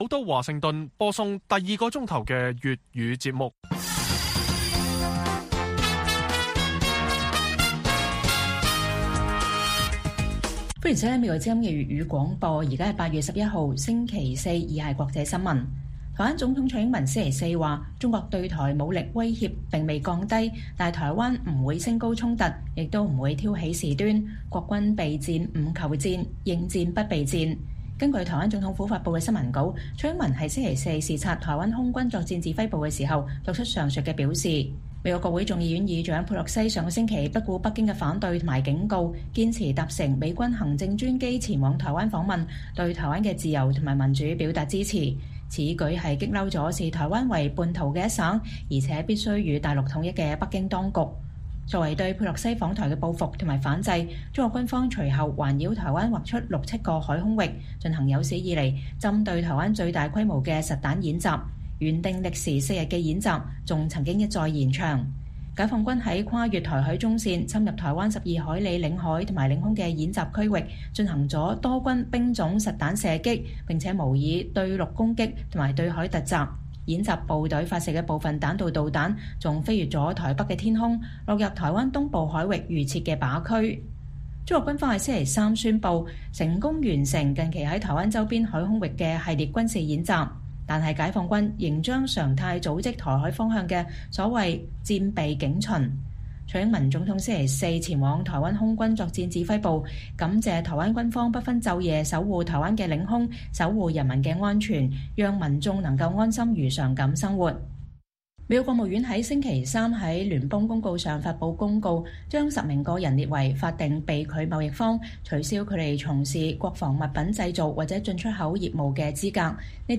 粵語新聞 晚上10-11點: 蔡英文說中國武力威脅未減，台灣備戰不求戰，應戰不避戰